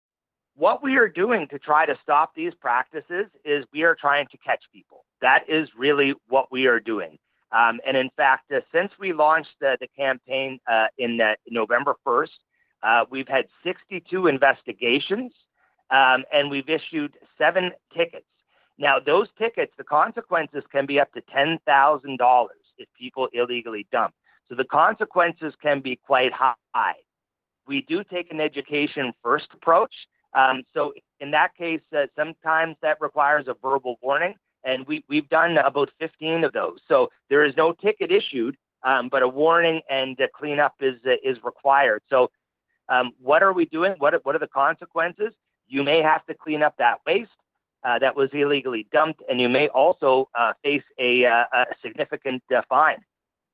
nous explique ce qu’il en est à l’occasion de l’entretien qu’il a accordé à OUI 98,5 FM